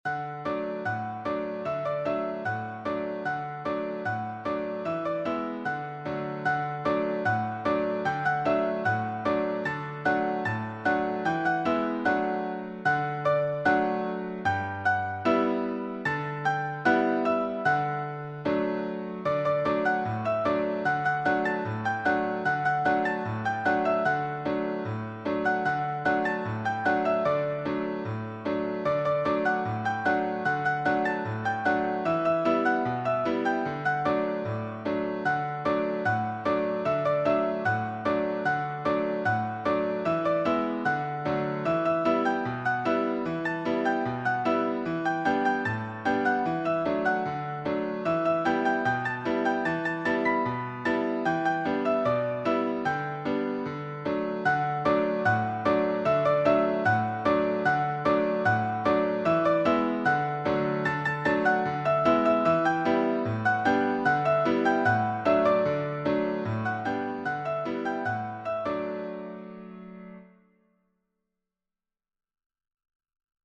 Playful
Piano and voice